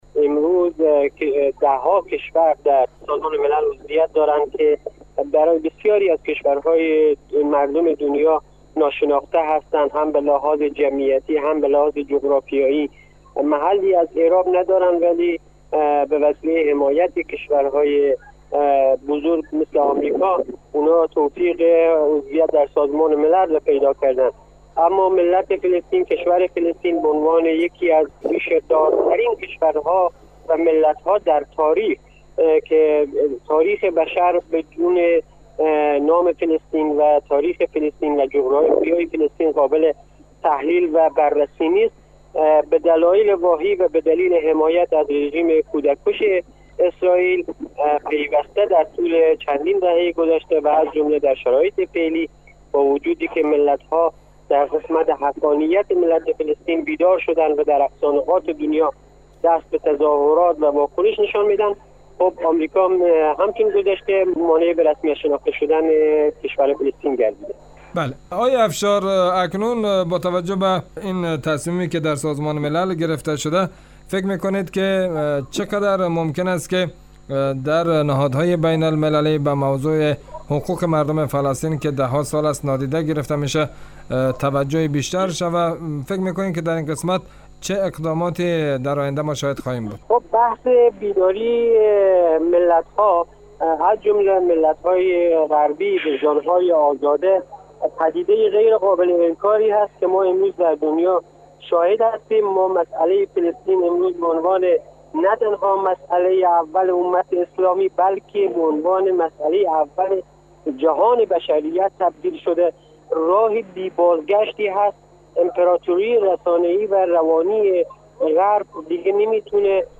در گفت و گو با برنامه انعکاس رادیو دری